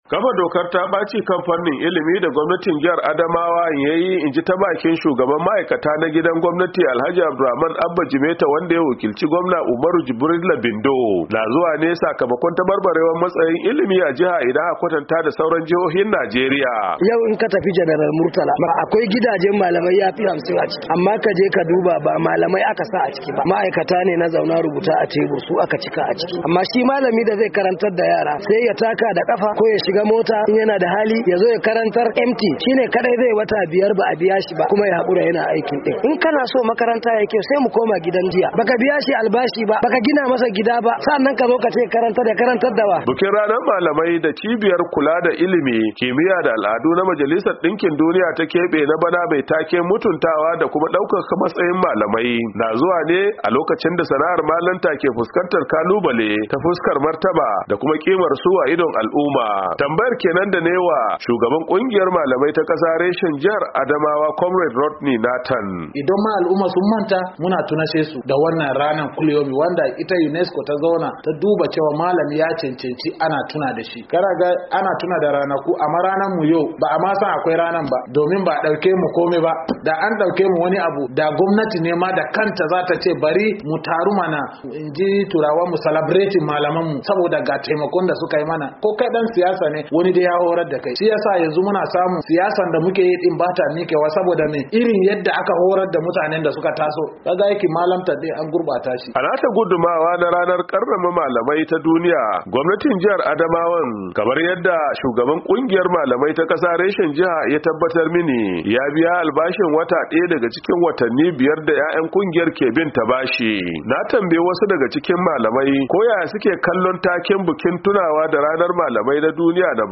Daga cikin bakin da